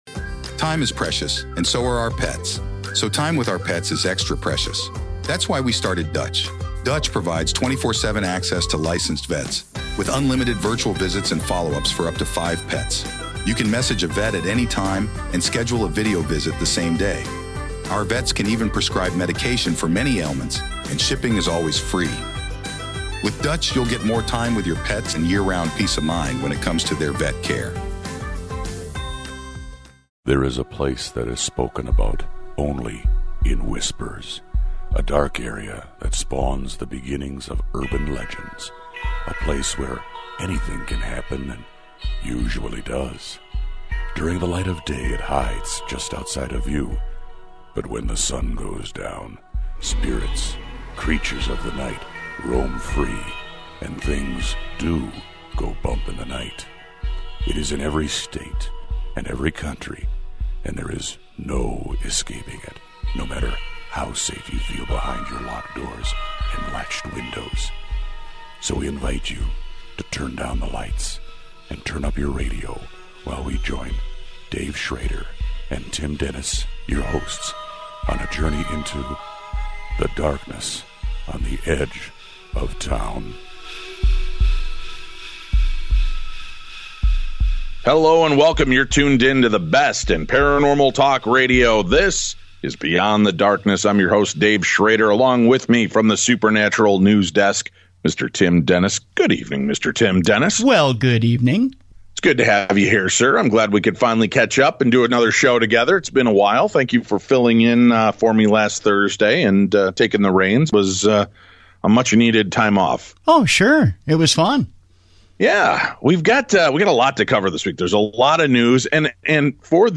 Hello and welcome. You're tuned in to the best in paranormal talk radio.